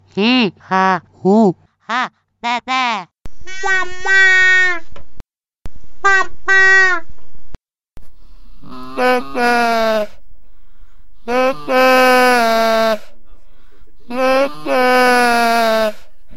Speech produced with a mechanical speaking machine